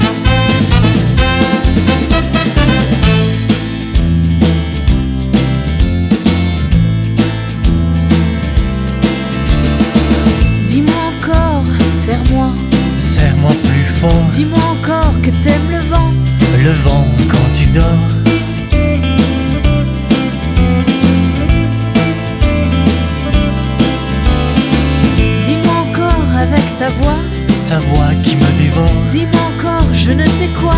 Le son est chaud, les deux voix s'accordent, se répondent.